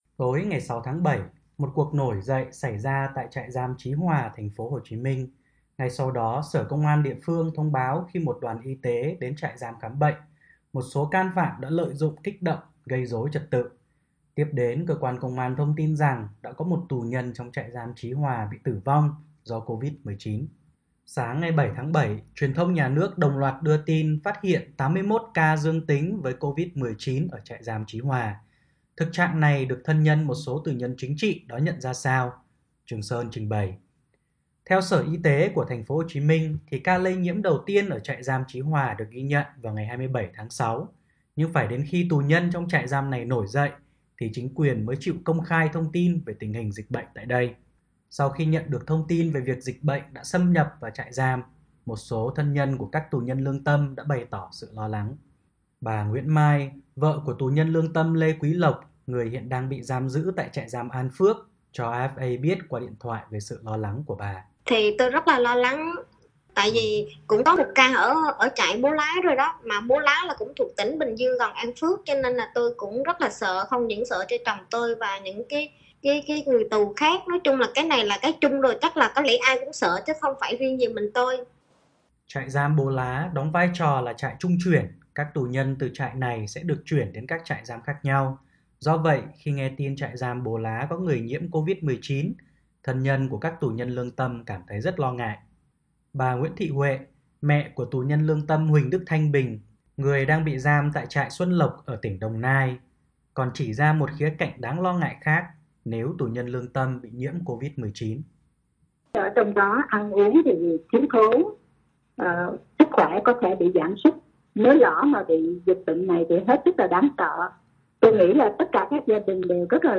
cho RFA biết qua điện thoại về sự lo lắng của bà.